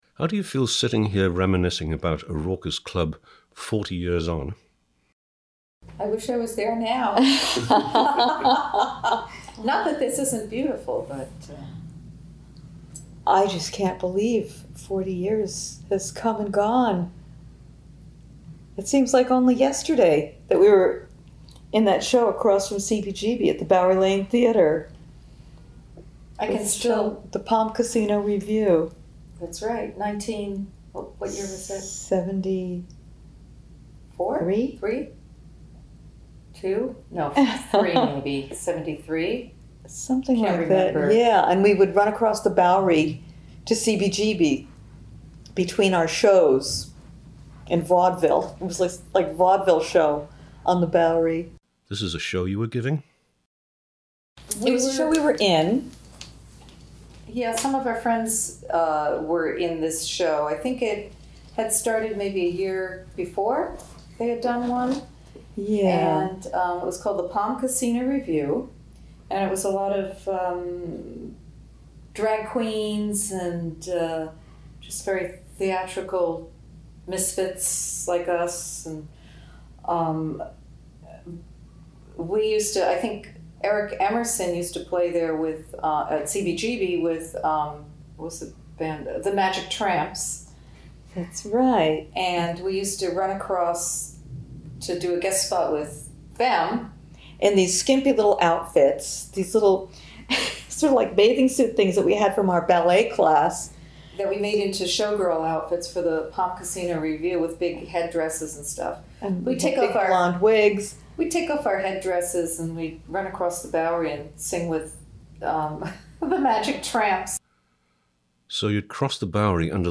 in interview
during a raging thunderstorm
next to the rain hammering on the windows